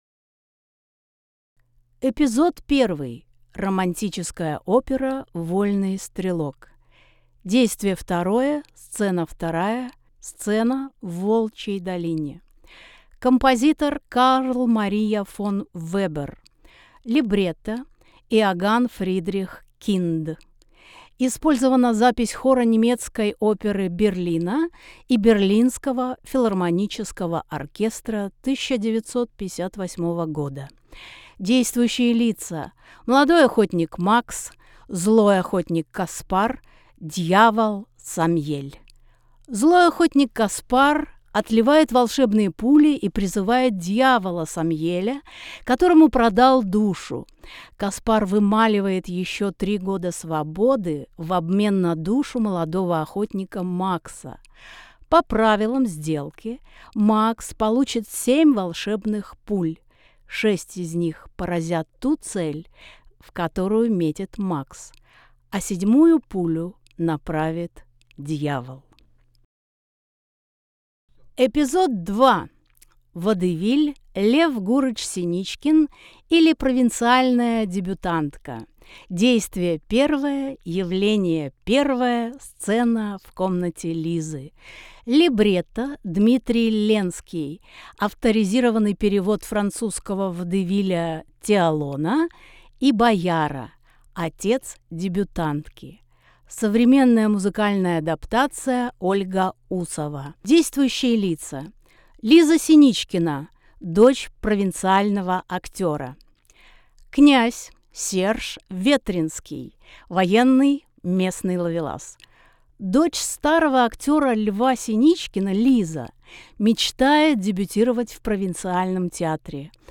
Тифлокомментарии к экспонатам выставки
Аудиогид. 1 этаж. МедиаВертеп. 7 постановок Аудиогид. 1 этаж. 1 зал.